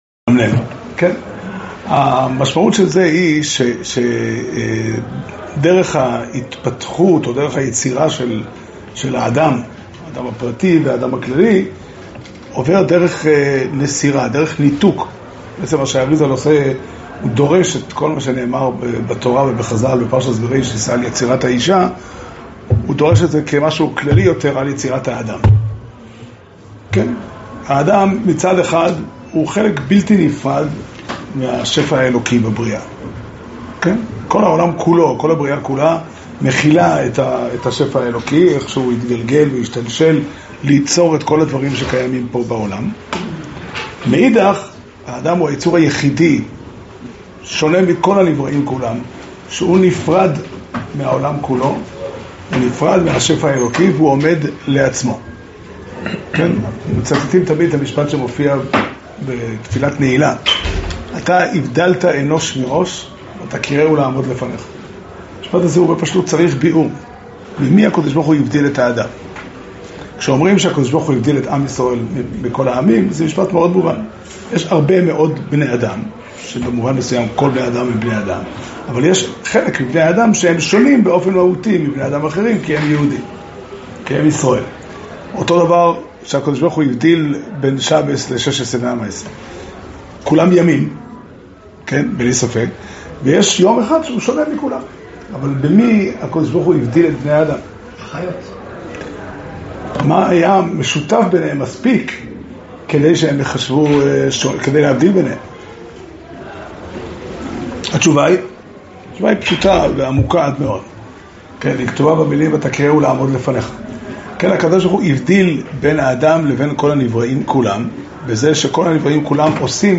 שיעור שנמסר בבית המדרש פתחי עולם